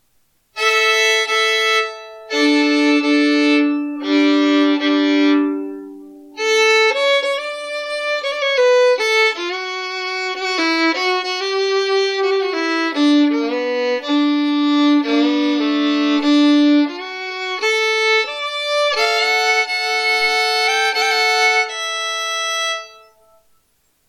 Vintage French JTL Breton Violin/Fiddle $1725